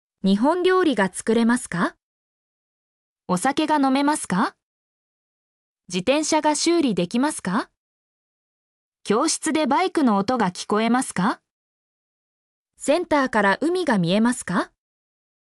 mp3-output-ttsfreedotcom-8_35EyAP0r.mp3